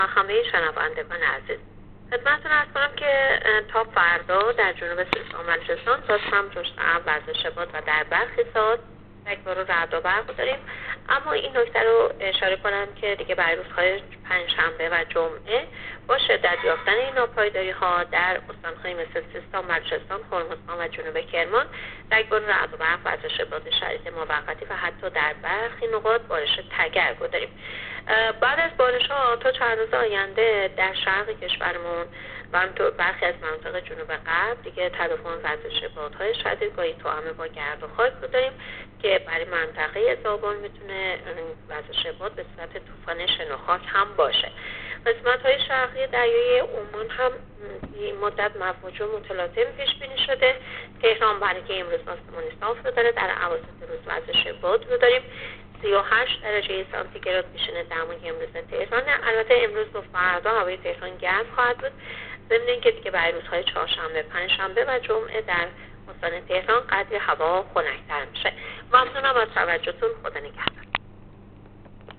گزارش رادیو اینترنتی پایگاه خبری از آخرین وضعیت آب‌وهوای بیست و چهارم مردادماه؛